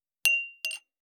287皿を重ねる,カチャ,ガチャン,カタッ,コトン,ガシャーン,カラン,カタカタ,チーン,カツン,カチャカチャ,ガタッ,キン,カン,コン,ゴトン,パリン,チャリン,カラカラ,シャリン,
コップ効果音厨房/台所/レストラン/kitchen食器